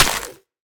Minecraft Version Minecraft Version snapshot Latest Release | Latest Snapshot snapshot / assets / minecraft / sounds / block / muddy_mangrove_roots / break2.ogg Compare With Compare With Latest Release | Latest Snapshot